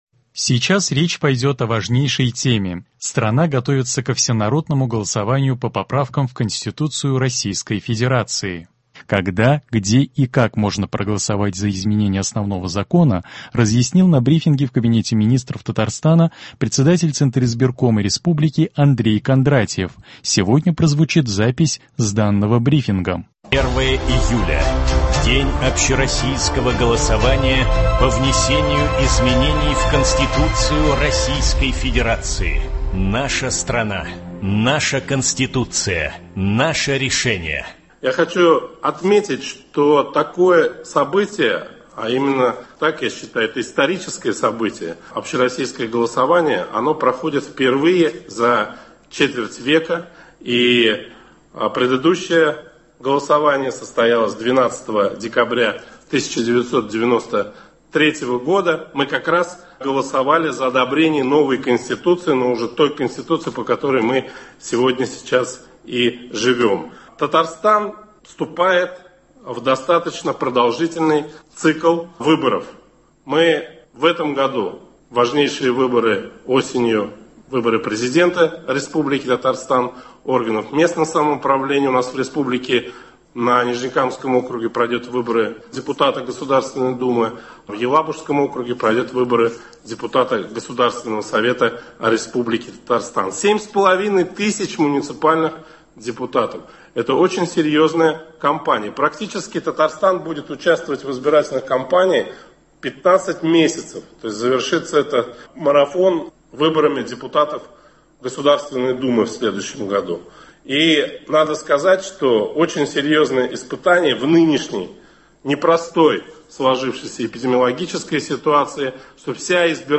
Когда, где и как можно проголосовать за изменения Основного закона — разъяснил на брифинге в Кабинете министров Татарстана председатель Центризбиркома республики Андрей Кондратьев.